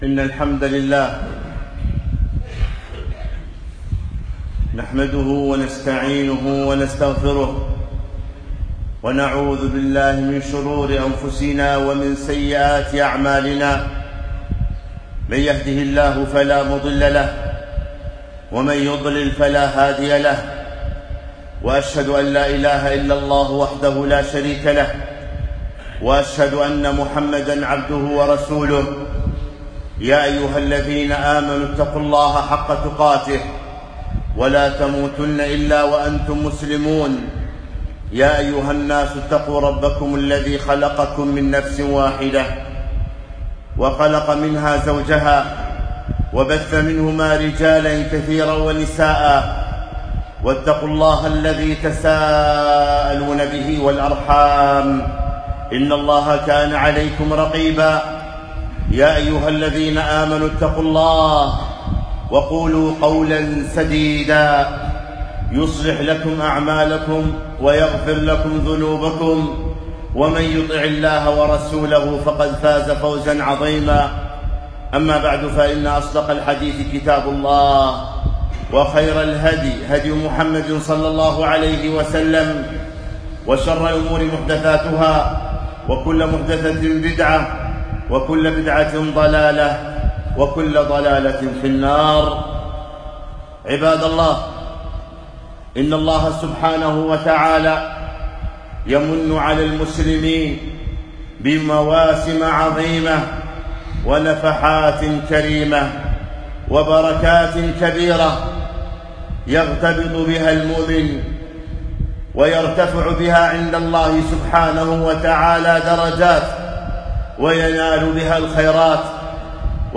خطبة - آداب وأحكام الصيام